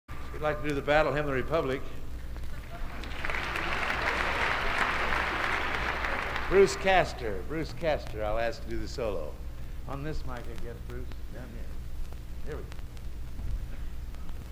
Location: West Lafayette, Indiana
Genre: | Type: Director intros, emceeing